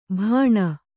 ♪ bhāṇa